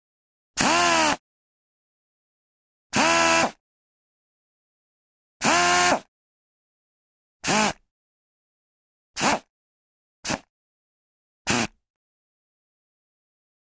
Small Pneumatic Drill; Spurts; Numerous Grinder Bursts, Varying Lengths, Air Release / Motor Spin, Close Perspective.